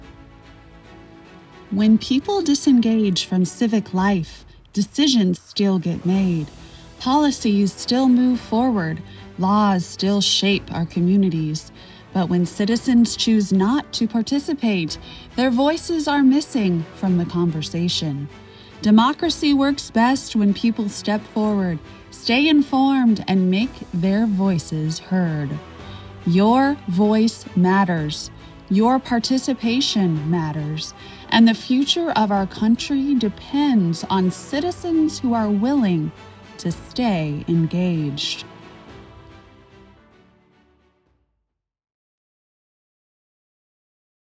Political
English - Midwestern U.S. English
Operate a broadcast-quality home studio featuring a Shure SM7B microphone and Universal Audio Apollo Twin interface, delivering clean WAV audio with quick turnaround and professional editing.
My voice has a calm, reassuring quality that helps listeners feel both informed and encouraged.